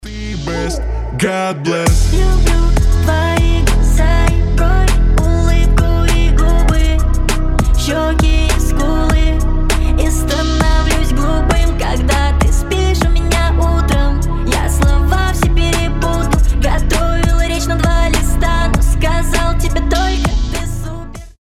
• Качество: 320, Stereo
мужской голос
забавные
милые
басы